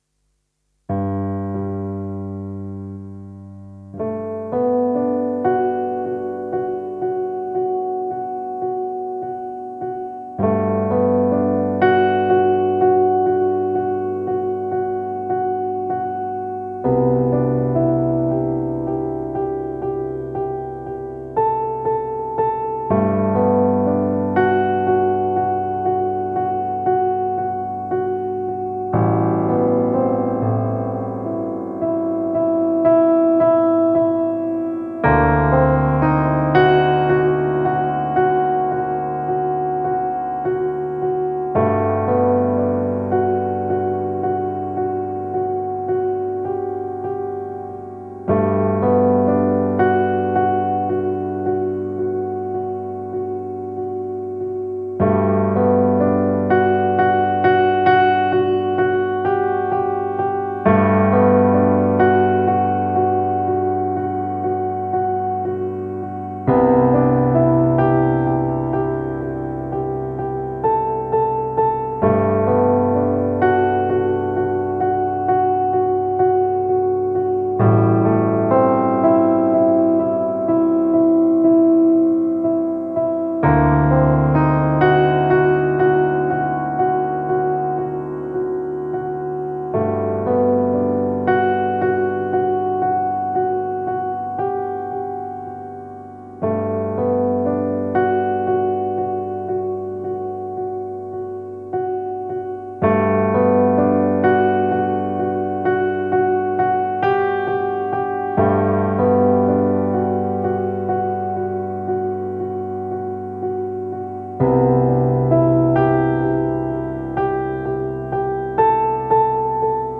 Chilled Lounge Piano.